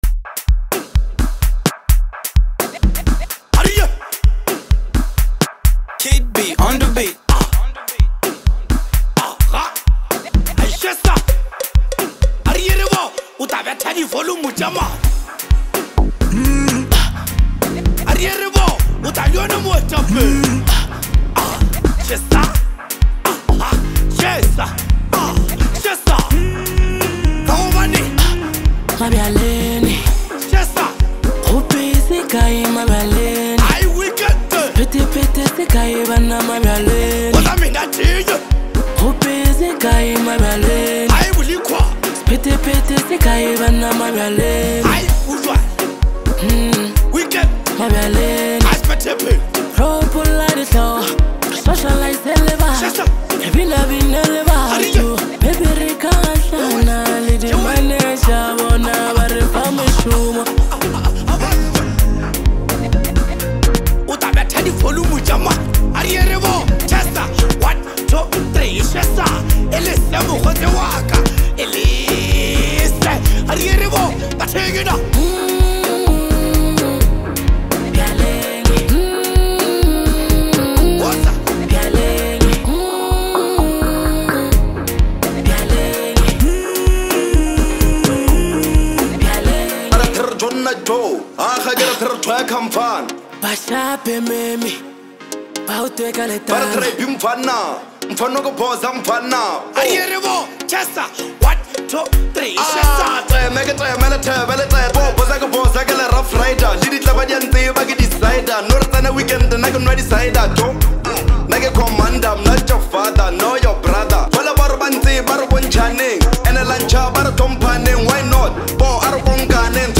Home » Amapiano » Gqom